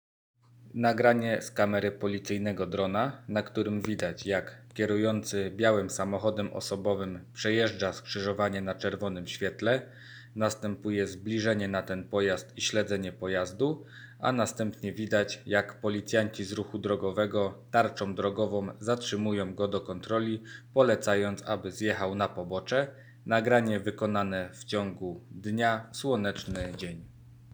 Nagranie audio Audiodeskrypcja filmu